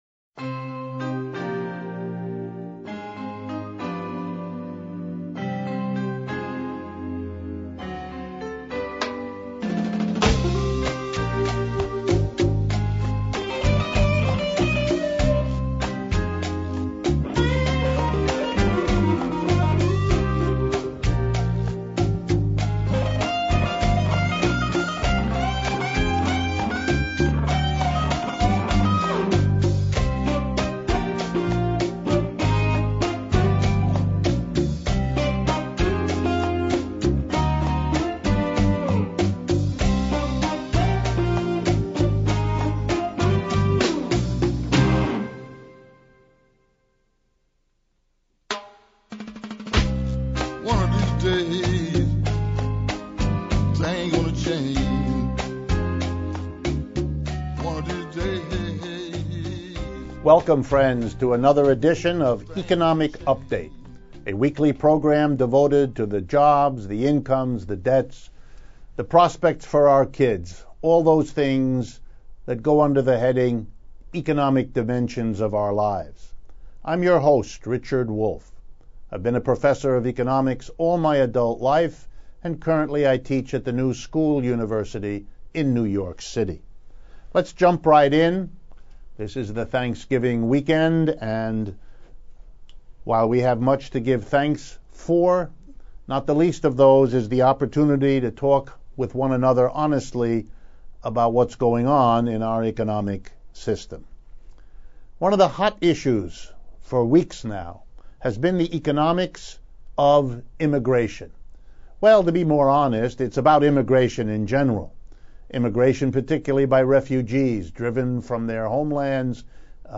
Updates on economics of immigration, economic alarmists, Ireland as tax haven. Response to listeners on role and place for small business in worker-coop based economy. Interview